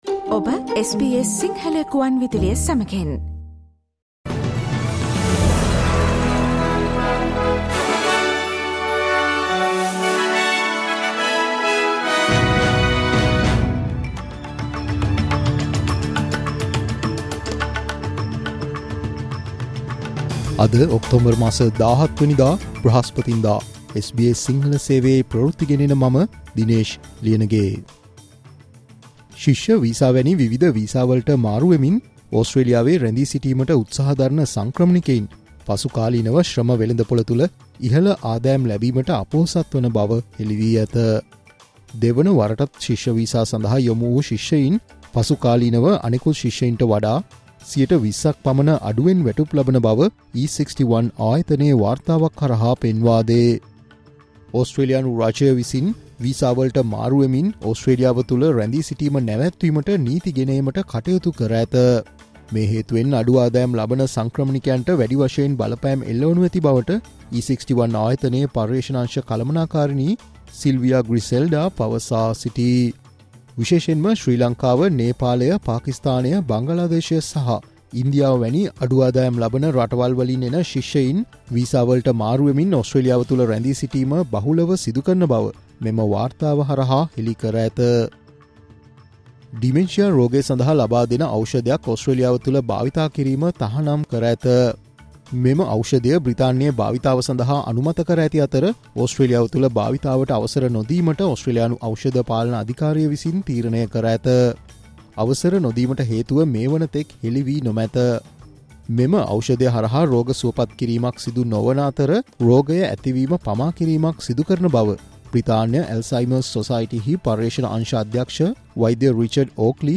New research on migrants switching between student visas in Australia: News Flash 17 Oct